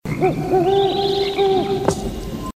OWL HOOTS IN THE FOREST.mp3
An owl surprised in a deep forest night, howling in the trees.
owl_hoots_in_the_forest_tr5.ogg